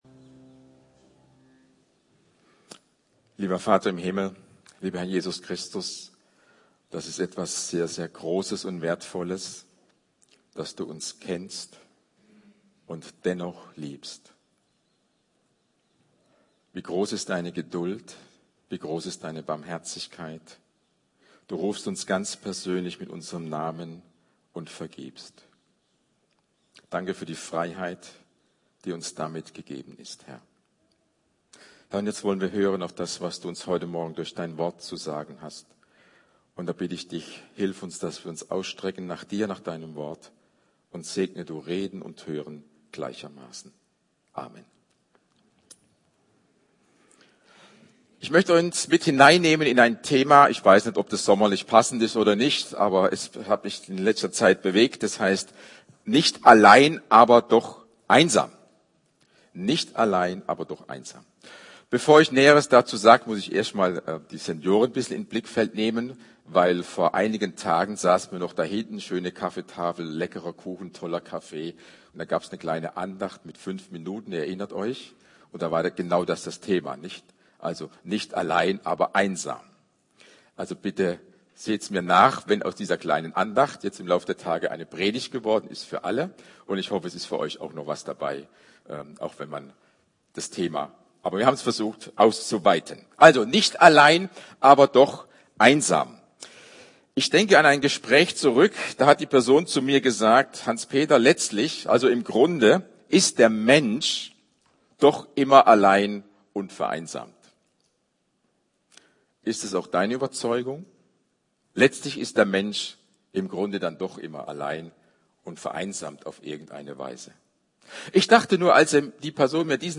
Gottesdienst vom 08.06.2025.
Lobpreis Alle Augen auf dich Geist des Vaters Danke (Danke für die Sonne) Lege deine Sorgen nieder The Heart of worship Heiliger Geist Tagged with Predigt Audio (MP3) Previous Pfingsten!